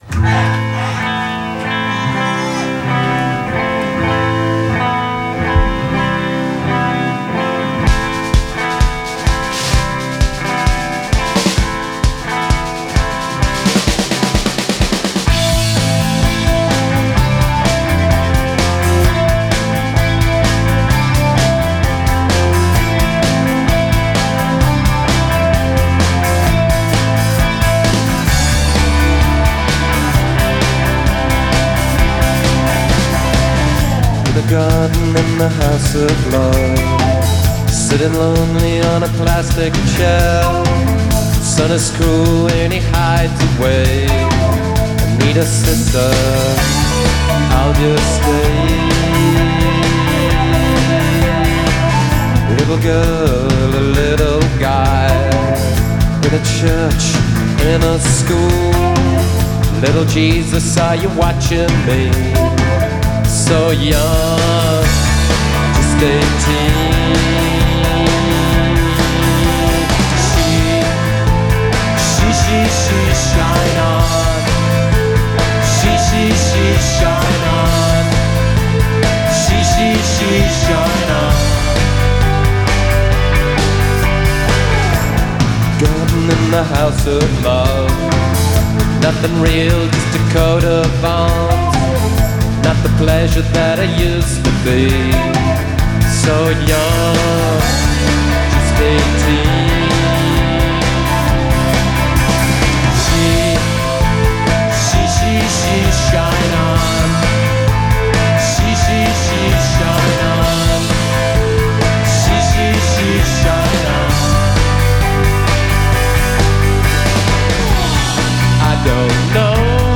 Recorded in Norwich 1992